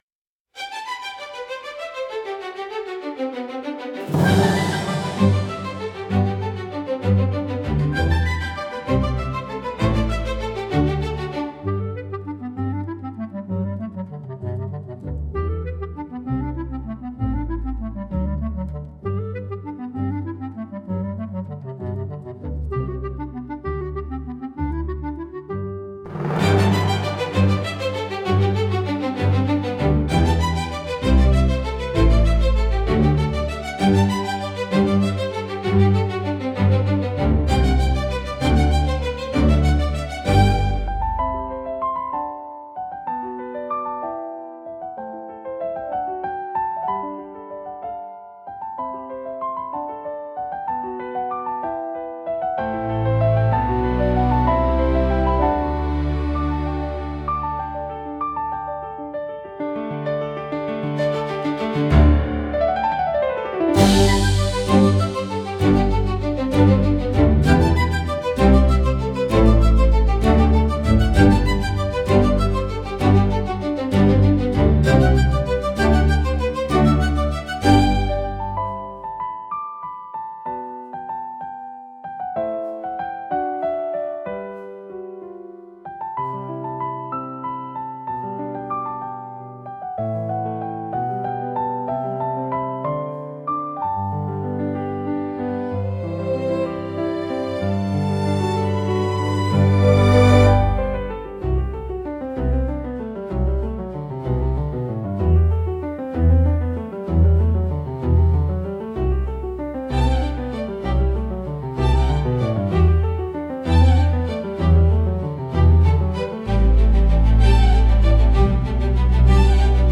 穏やかで柔らかなメロディとアレンジが、聴く人に安心感と心地よさをもたらします。
オリジナルの幸せは、幸福感や温かさを感じさせる優しい曲調が特徴のジャンルです。